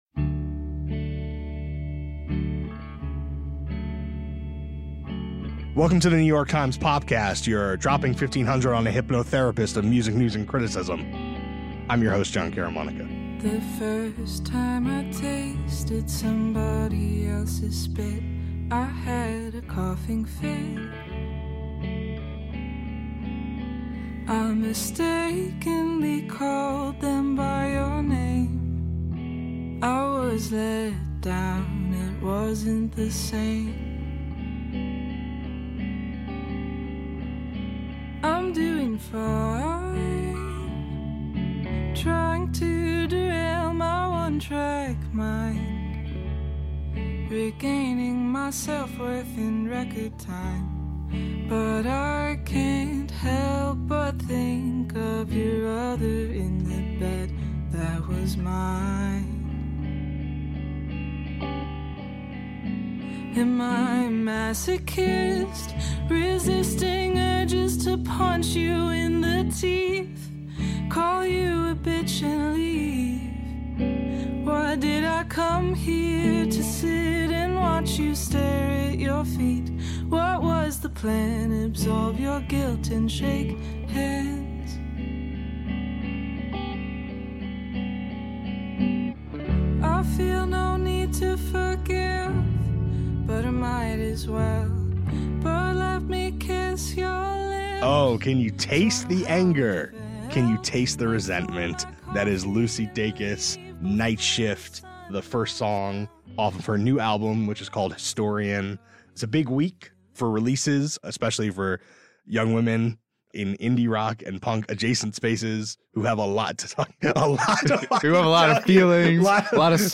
A conversation about carefully considered creative paths — and how pristine voices with powerful messages are amplified — as three strong new albums arrive